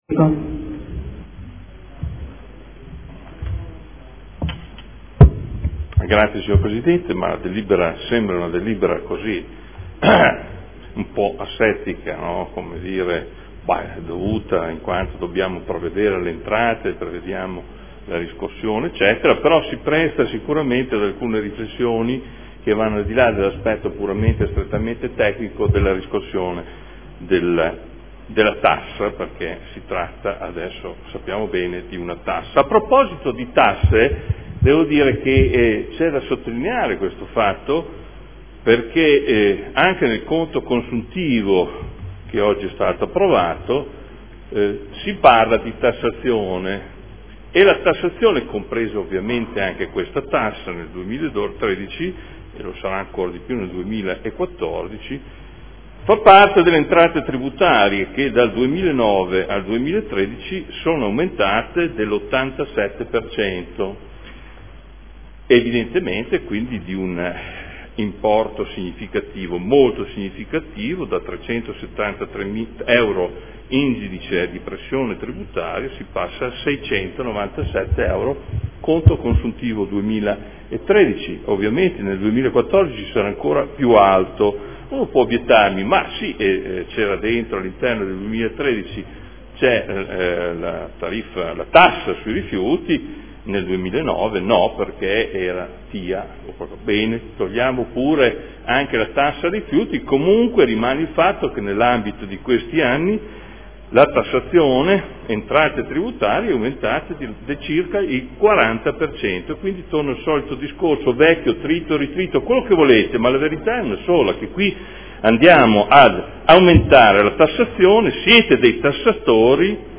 Seduta del 28/04/2014. Convenzione per l’affidamento della gestione della riscossione del Tributo comunale sui Rifiuti (TARI) e per la regolamentazione della fatturazione e dei pagamenti del Servizio di gestione dei rifiuti urbani ed assimilati (SGRUA)